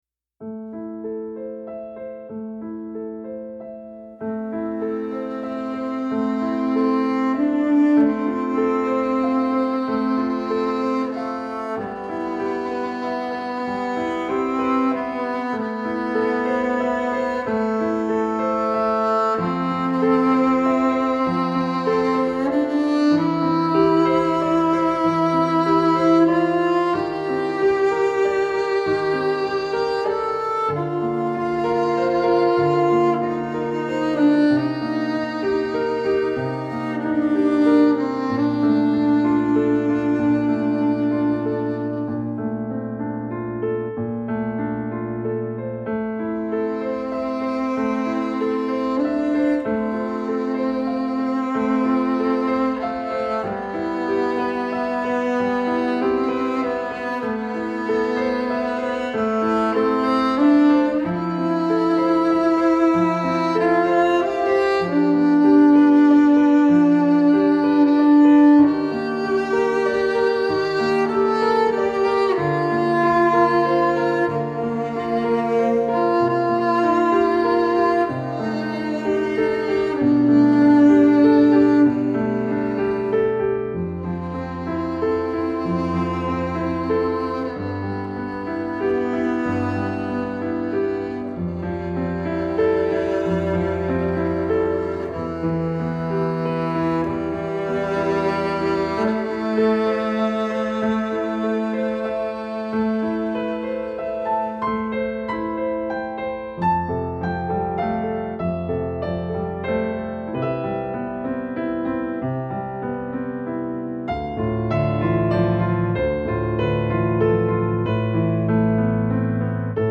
Classical Crossover
پیانو
ویولن